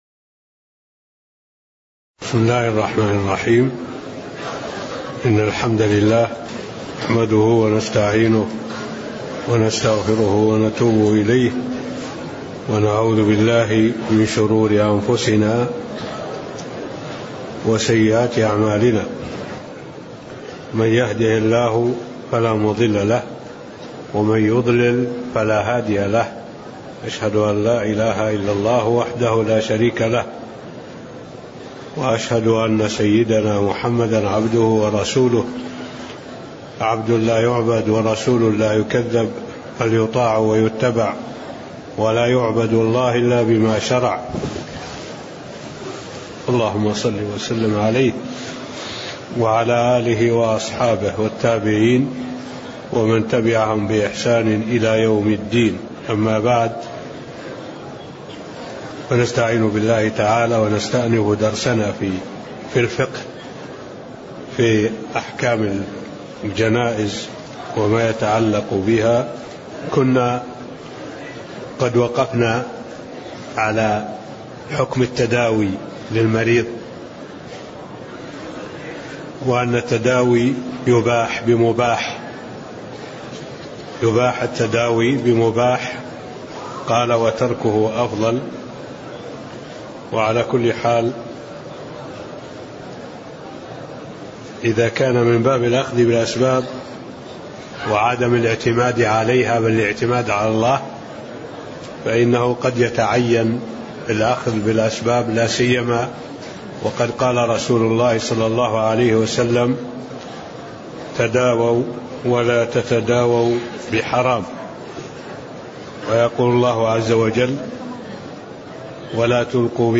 تاريخ النشر ٢٨ ذو الحجة ١٤٢٨ هـ المكان: المسجد النبوي الشيخ